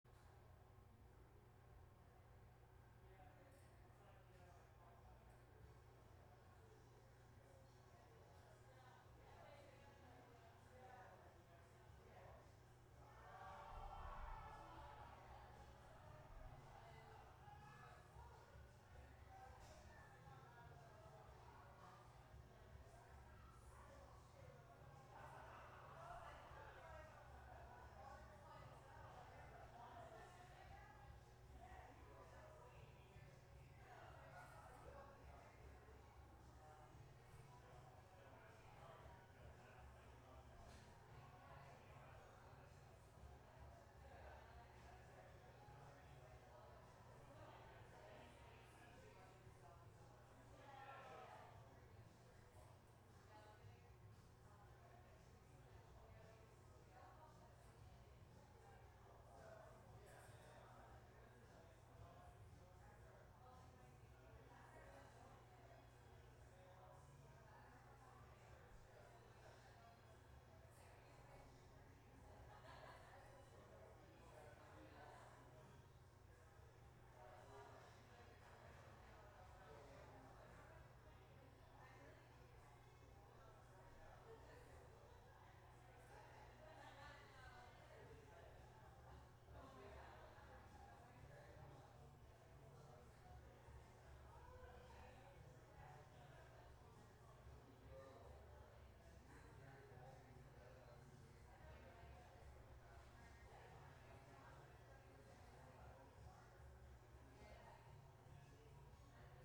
From a live webstream at The Avalon Lounge.